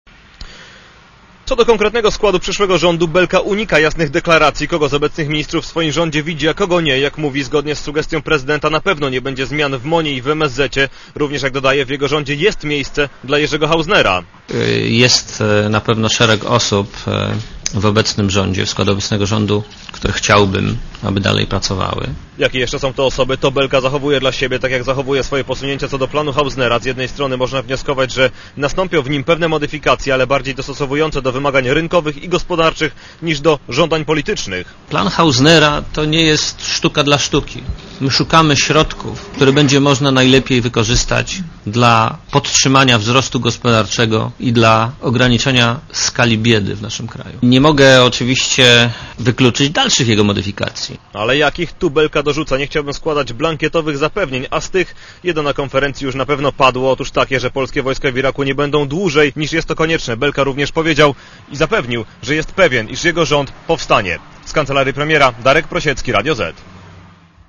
Relacja reportera Radia Zet
belkakonferencja.mp3